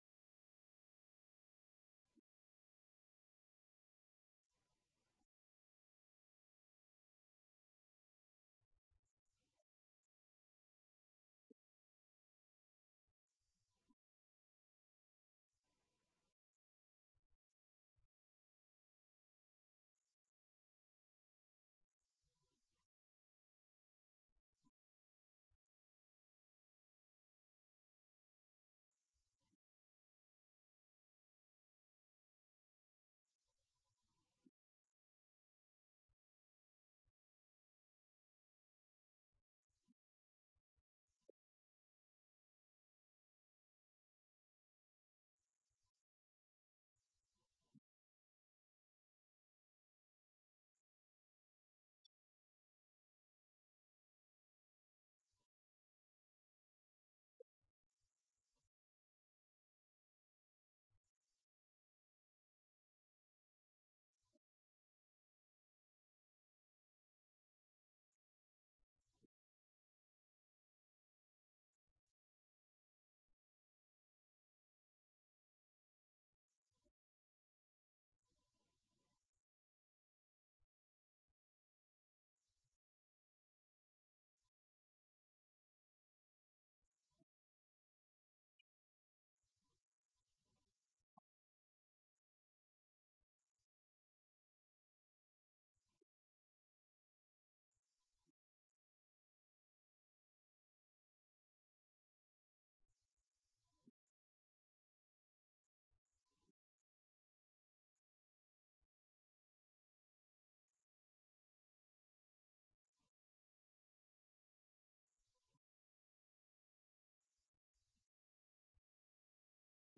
Event: 2019 Men's Development Conference
lecture